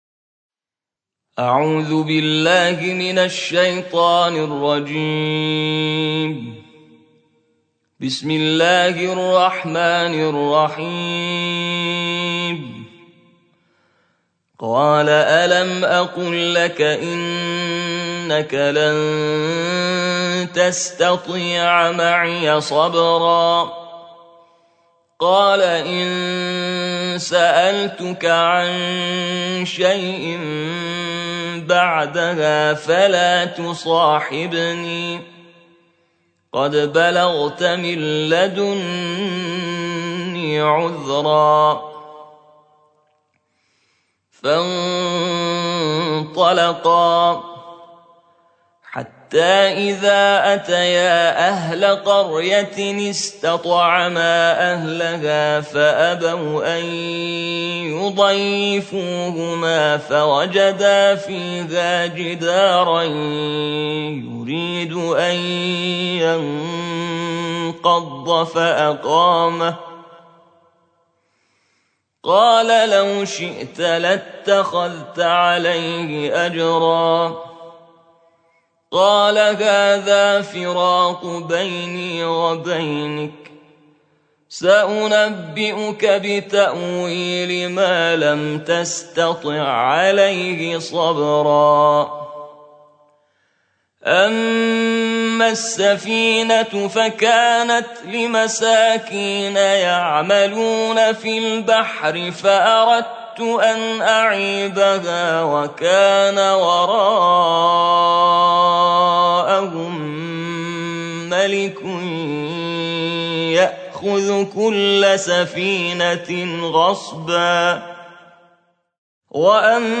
ترتیل جزء شانزدهم قرآن